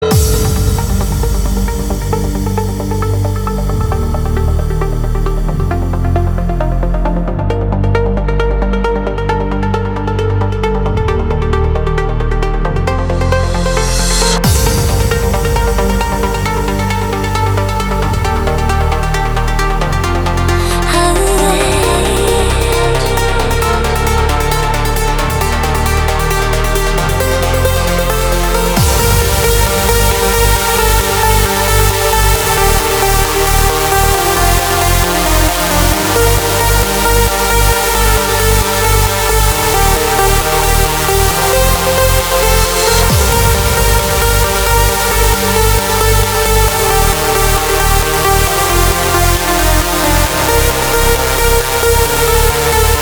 Транс - это музыка чувств, музыка свободы, музыка мечты...